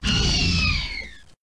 Звуки раптора